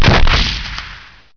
defabShoot.ogg